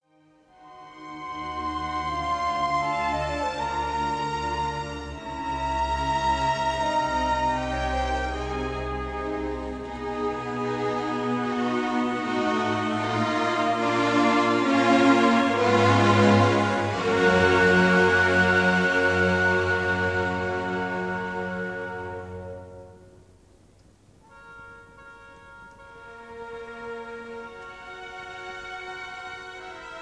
This is a 1952 recording of
conductor